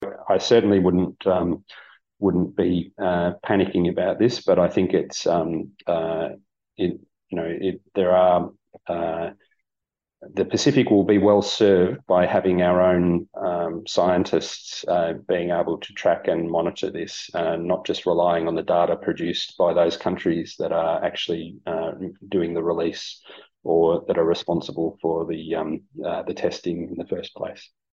Speaking via a Zoom session, the Director General of the Pacific Community Dr Stuart Minchin stresses that scientific data suggests the potential impact on the Pacific Ocean is unlikely to be significant.